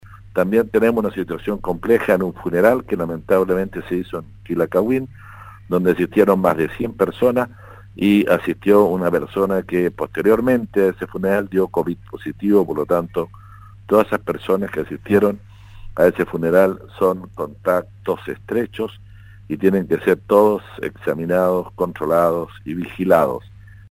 En conversación con Radio Sago, el Intendente Harry Jürgensen destacó las medidas que se están implementando para contener el virus, pero reconoció que hoy el foco de preocupación es el notorio aumento de casos en Osorno.